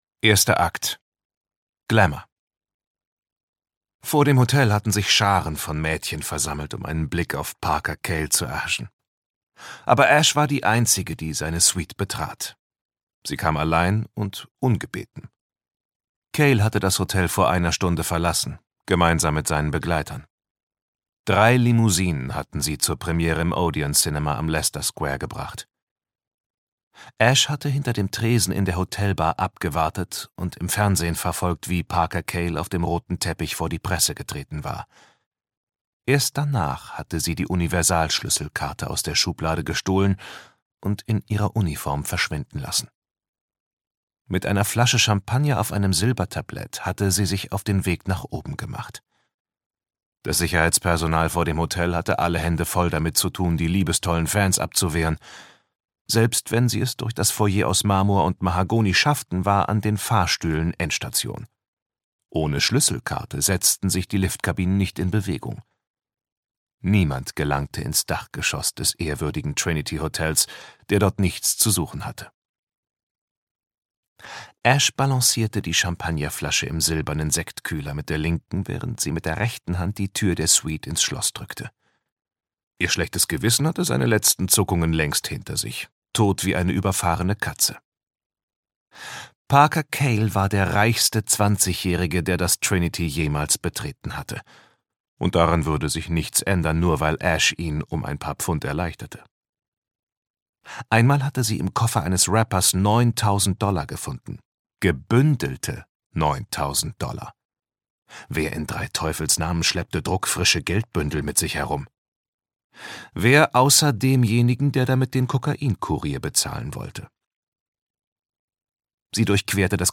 Asche und Phönix - Kai Meyer - Hörbuch